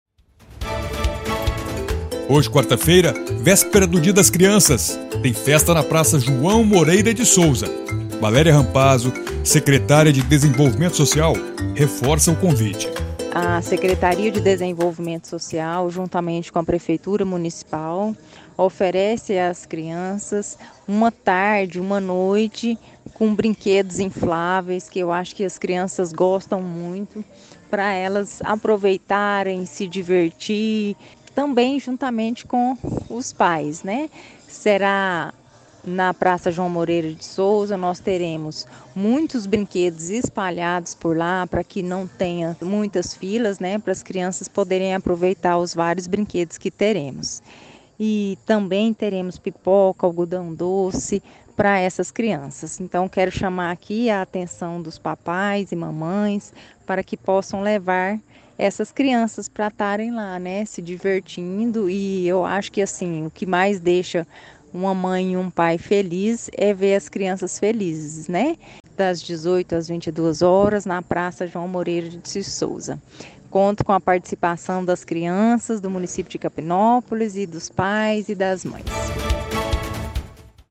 Valéria Rampazzo, secretária de Desenvolvimento Social, faz o convite para que os pais ou responsáveis levem as crianças.